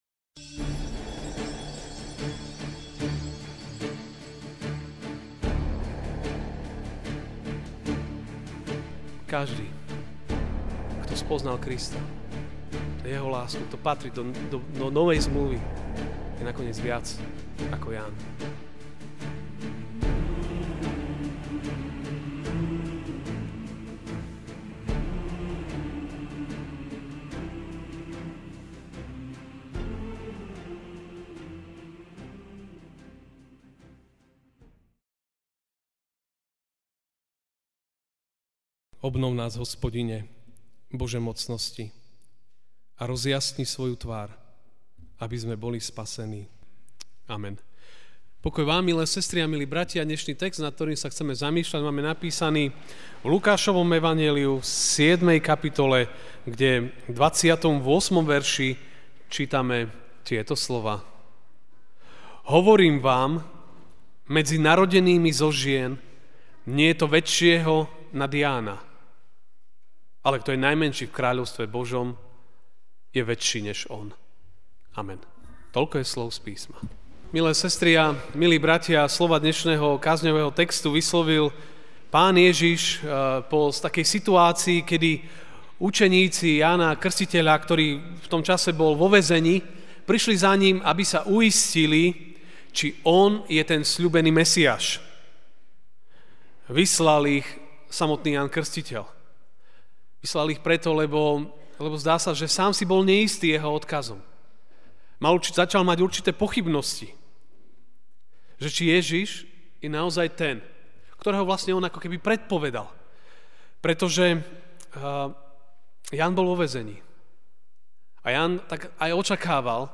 Ranná kázeň: Ján Krstiteľ.